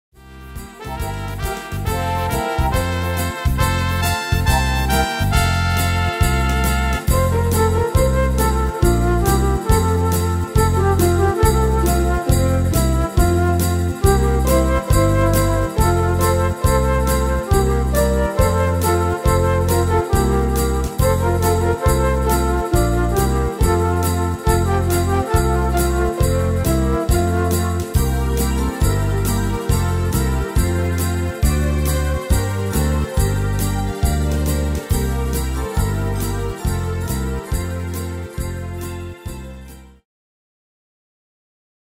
Tempo: 138 / Tonart: F-Dur